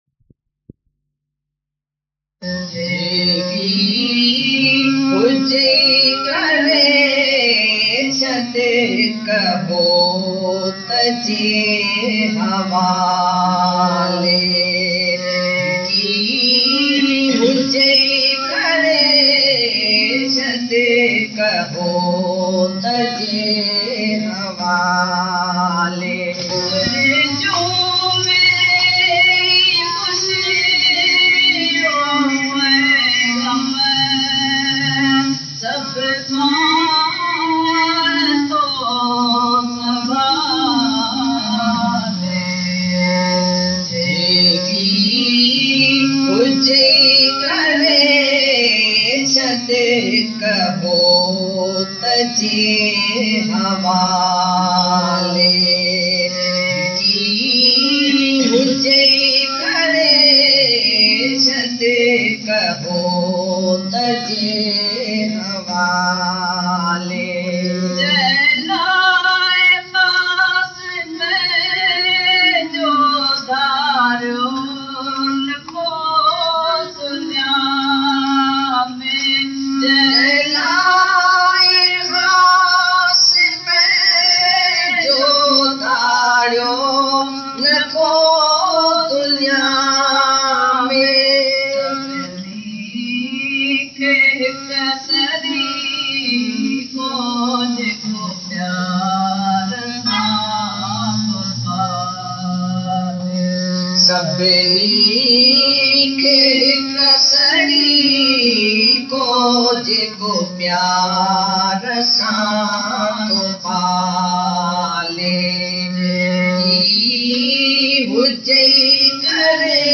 Jeki Huje Kare Chadi Bhajan | जेकी हुजई करे छदि भजन - DGSM Bhajan Lyrics